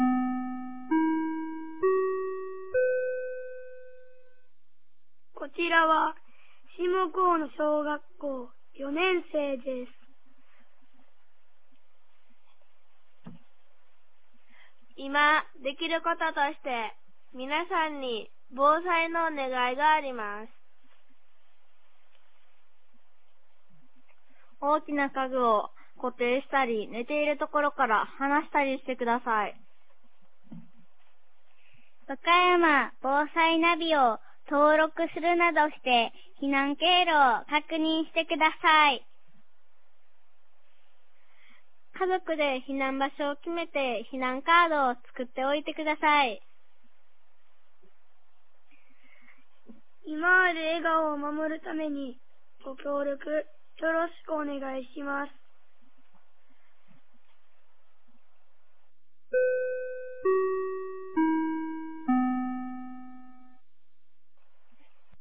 2024年02月19日 15時31分に、紀美野町より全地区へ放送がありました。